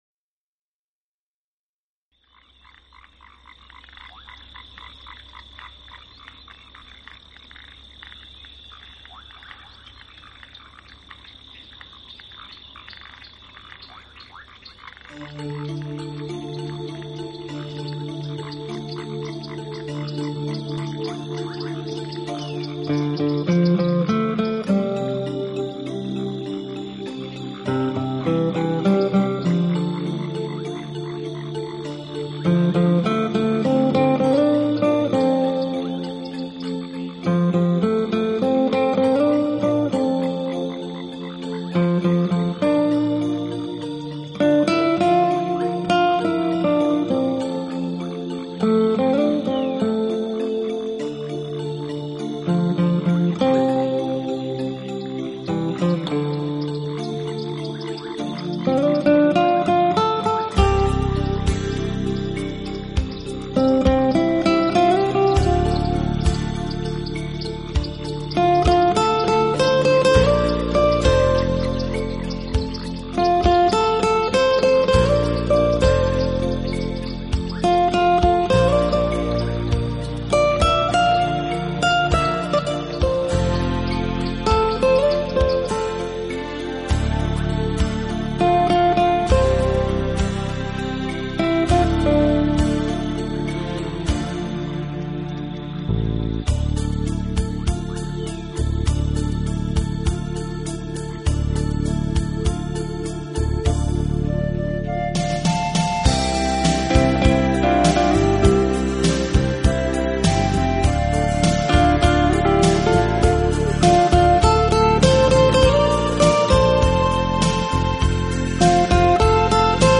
简单而清新的吉他声，Bossa Nova的慵懒节奏，
辑的曲子是绝对松驰慵懒，放下压力，最适合在午后炙阳下，饮啜一